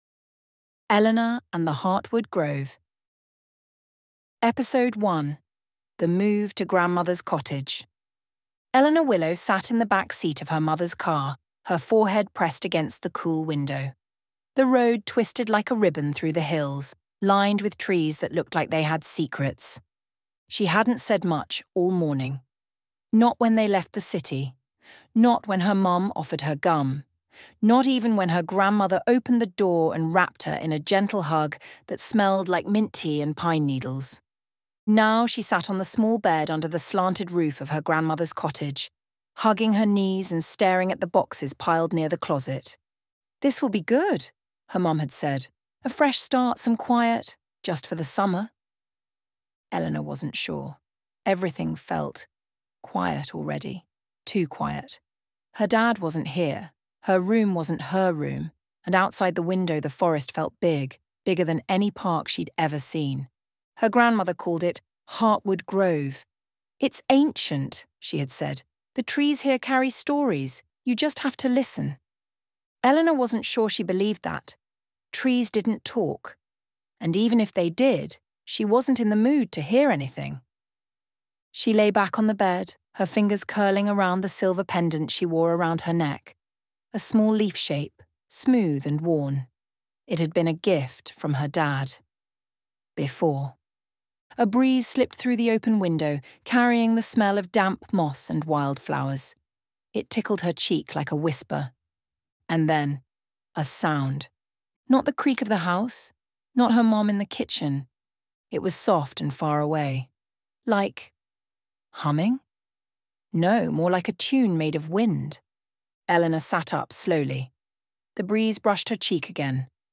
👉 Listen to the Audiobook: Chapter One